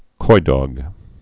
(kīdôg, -dŏg)